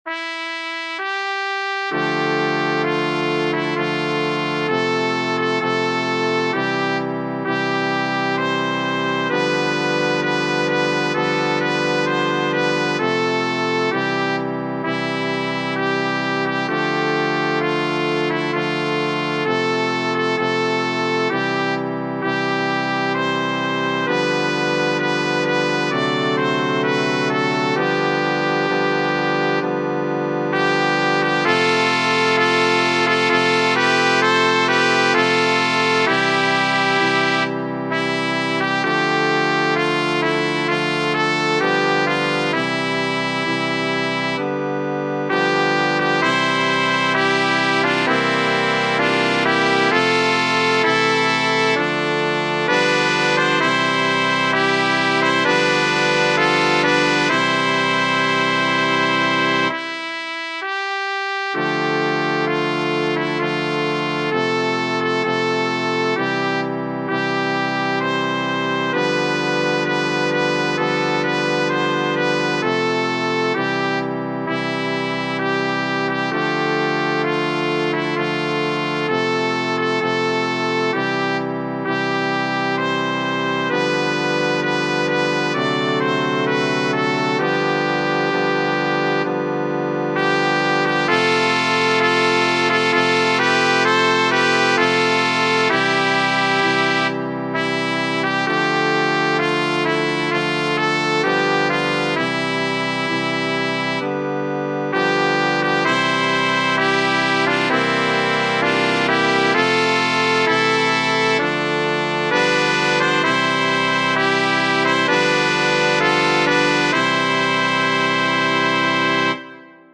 Tradizionale Genere: Religiose Canto mariano tradizionale Testo di anonimo Vergin Santa, che accogli benigna Chi t'invoca con tenera fede, Volgi lo sguardo dall'alta tua sede alle preci d'un popol fedel.